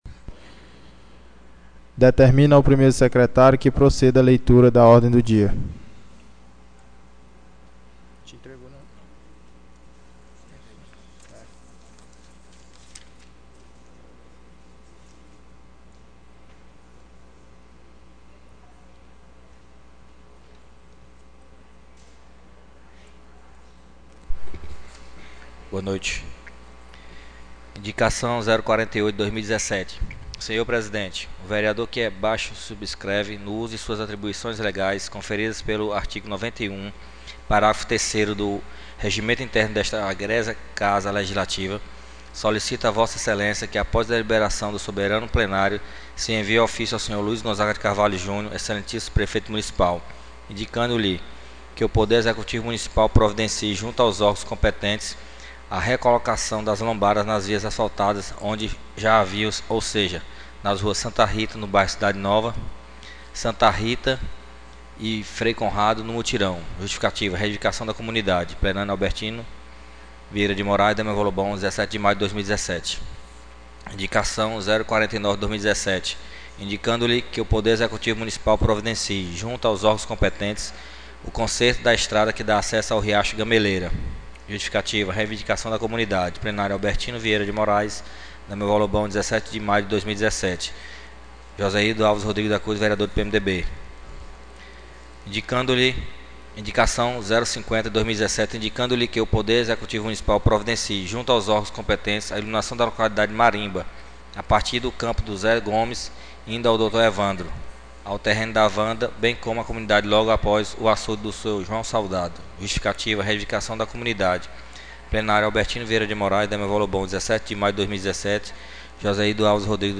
10ª SESSÃO ORDINÁRIA 17/05/2017